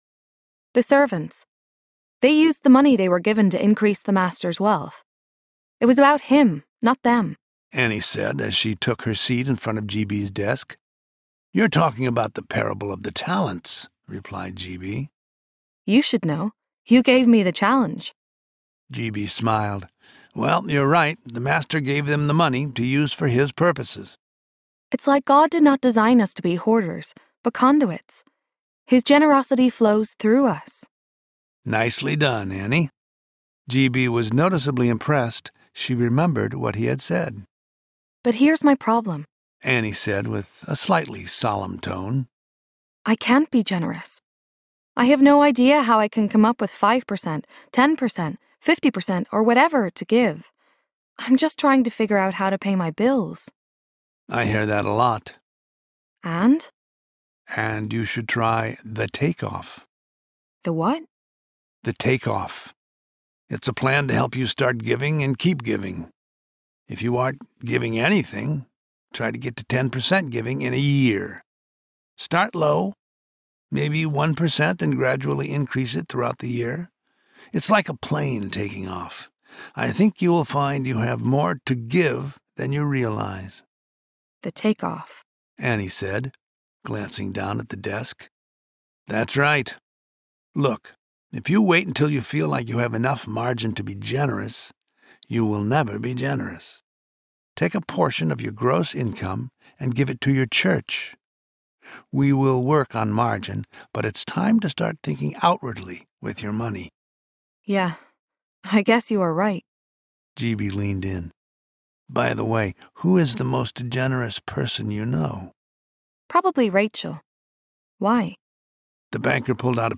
The Money Challenge Audiobook
Narrator